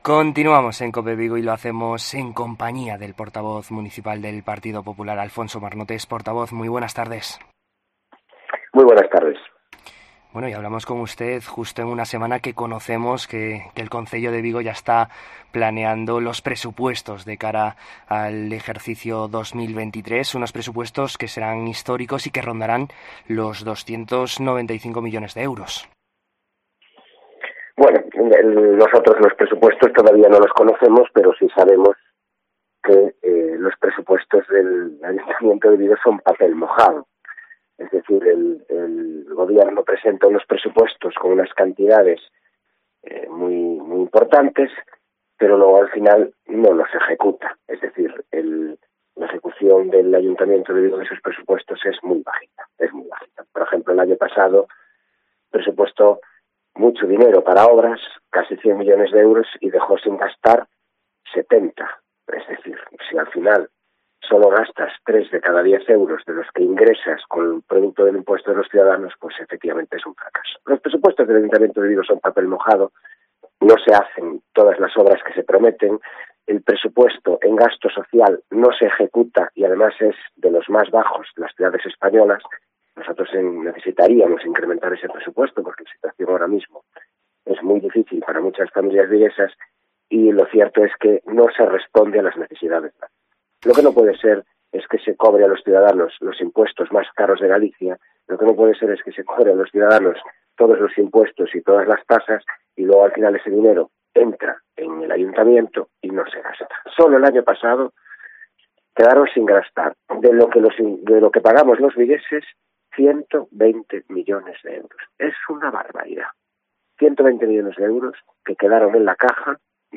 En COPE Vigo conocemos la actualidad municipal de la mano del portavoz del PP de Vigo, Alfonso Marnotes